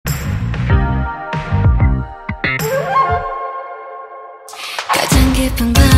Dusty Beats & Chilled Hip-Hop by Sample Magic
dch_100_flutes_loop_boujee_Fm